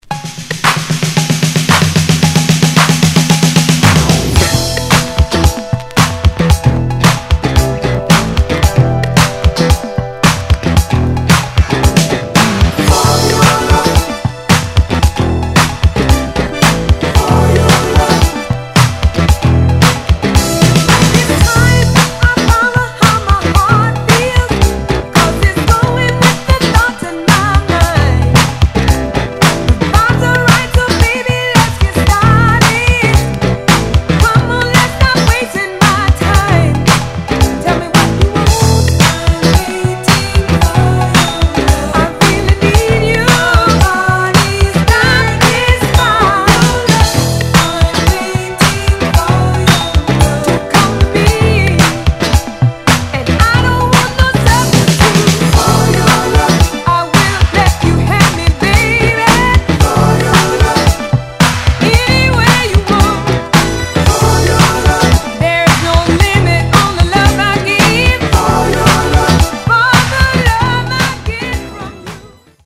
・ 45's SOUL / FUNK / DISCO / JAZZ / ROCK
80's Dance Classic!!